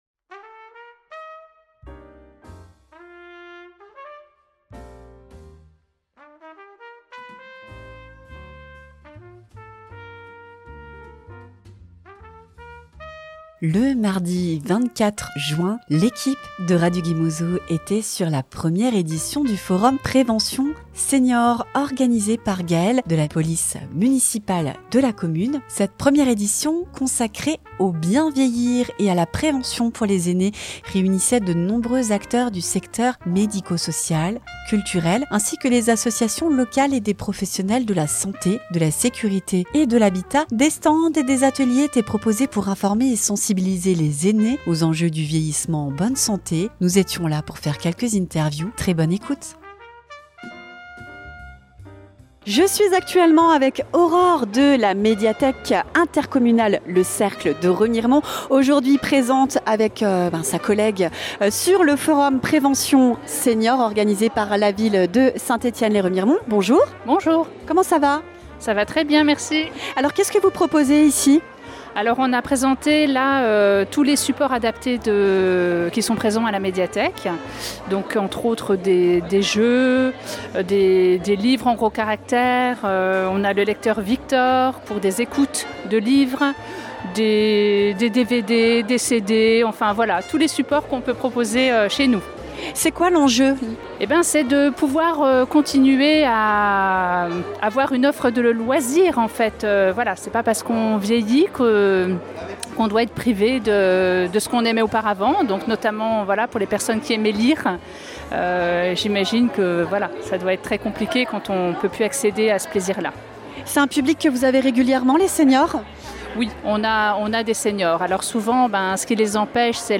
Radio Gué Mozot était sur place pour recueillir les témoignages et interviews des participants et intervenants.